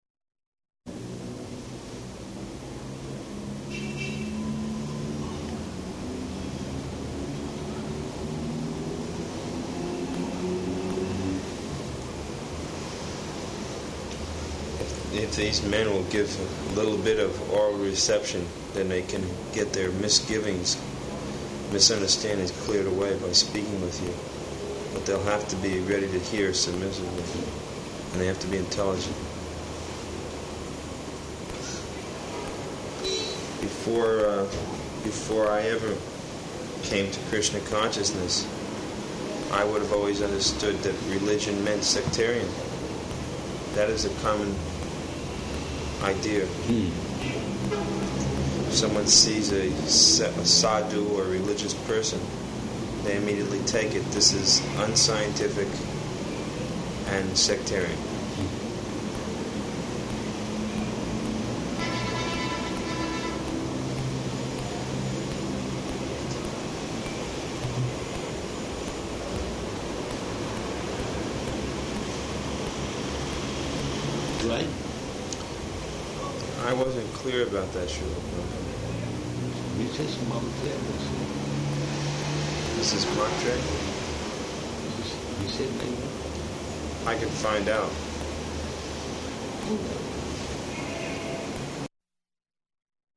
Room Conversation
Location: Bombay